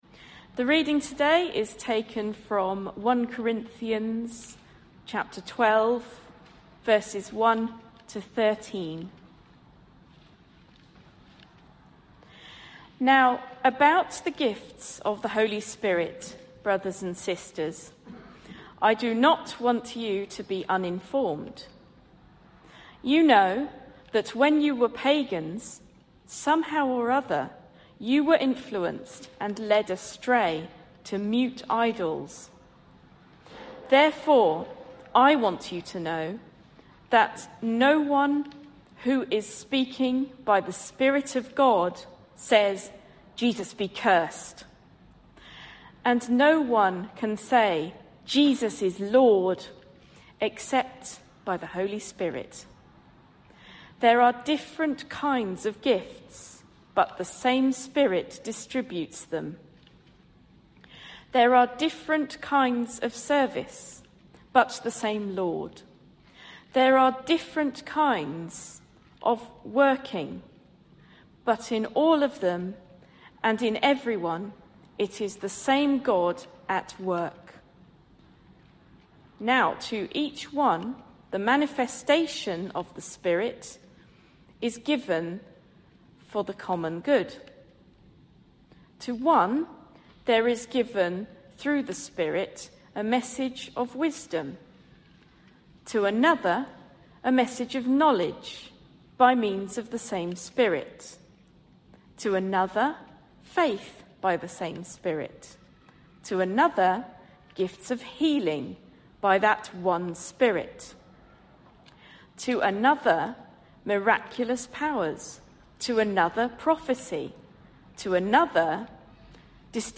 Another aspect of leading worship is reading from the Bible.